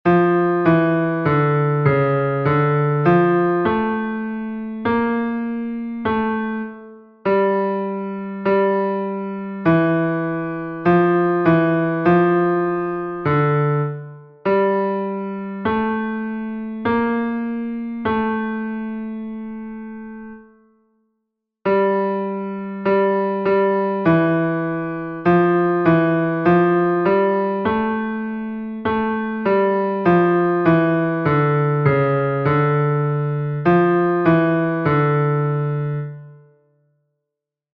keeping the beat exercise 1 F-clef